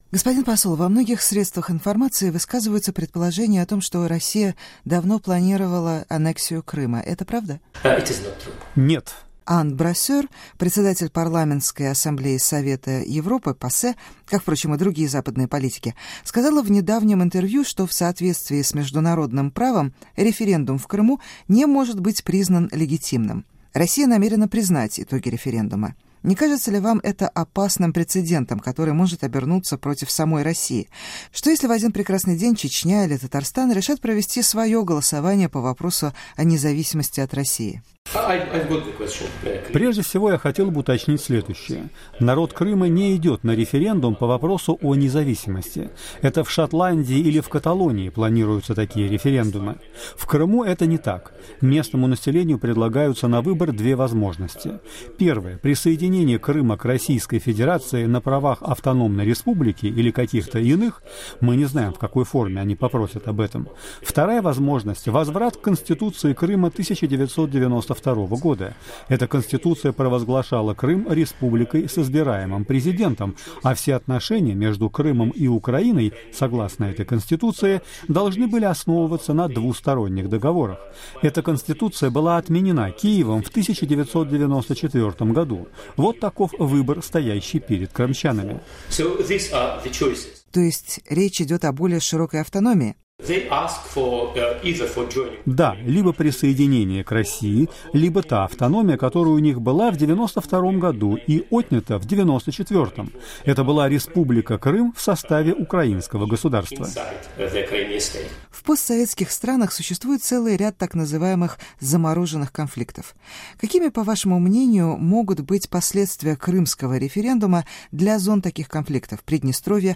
Интервью с постпредом РФ при Совете Европы Александром Алексеевым